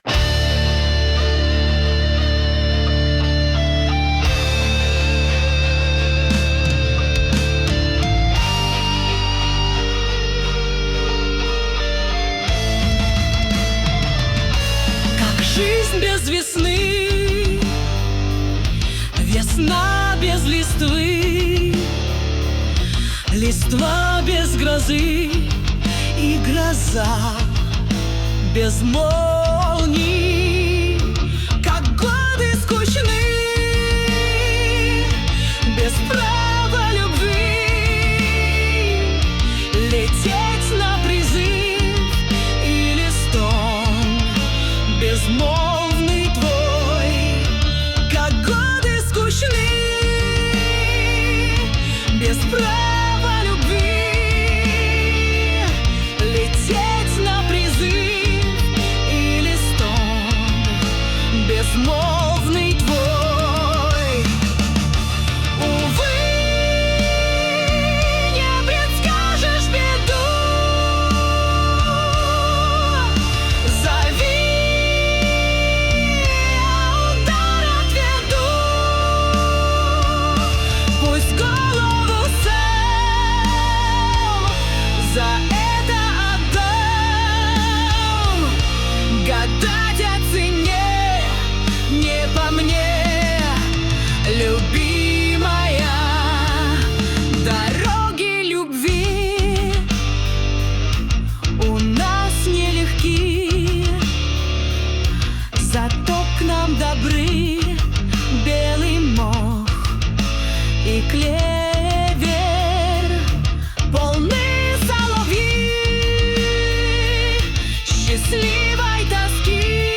Cover В Hard Rock Стиле